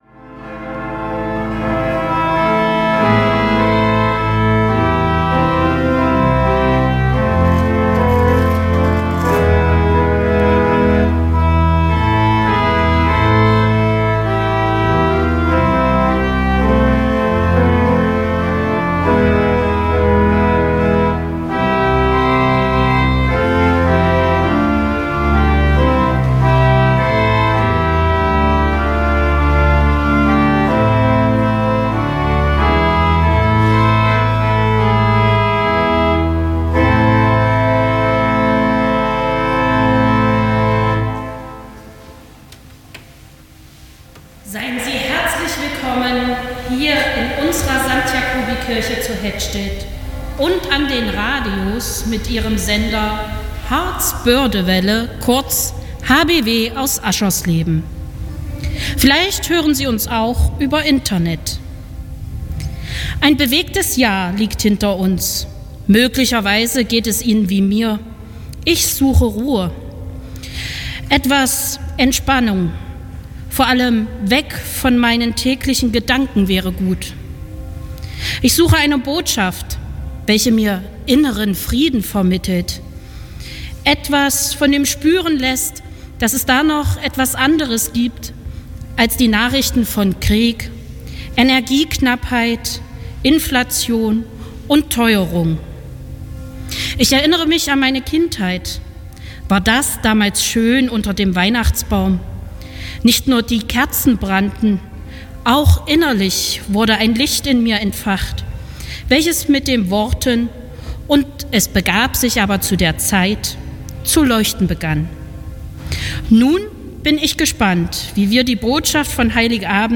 radio hbw hat auch in diesem Jahr an Heiligabend die Christvesper live aus der Hettstedter Jakobikirche übertragen.
christvesper_jakobikirche_hettstedt_2022.mp3